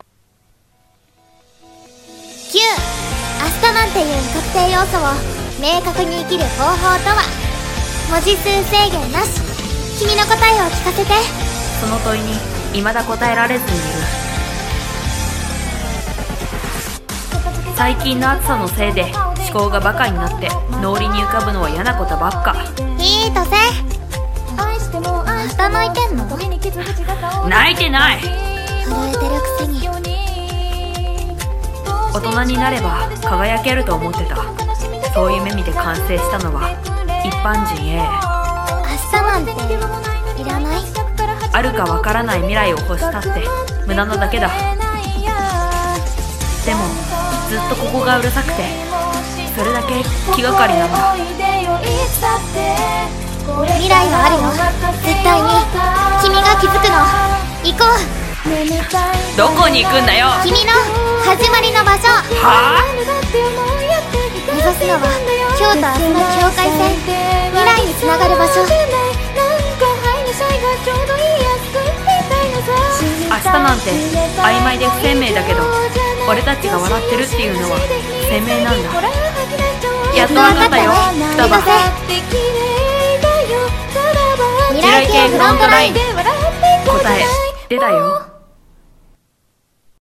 【二人声劇】未来繫フロントライン